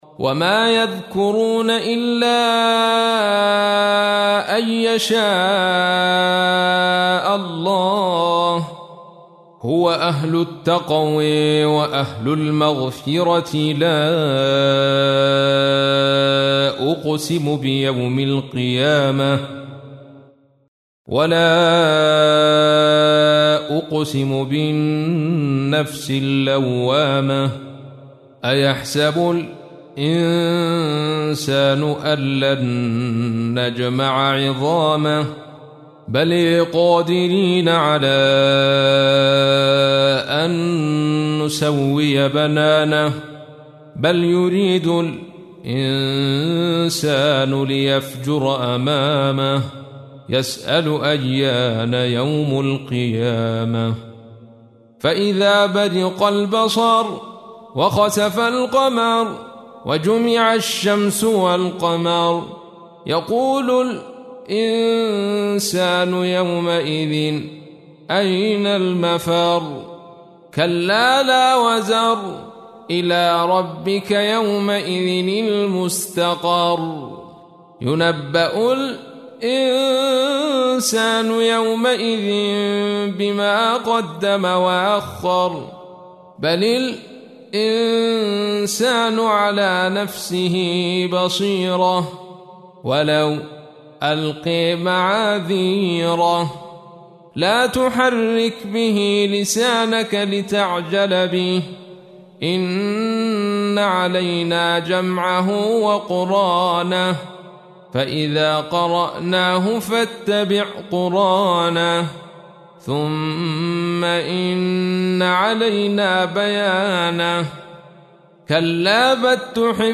تحميل : 75. سورة القيامة / القارئ عبد الرشيد صوفي / القرآن الكريم / موقع يا حسين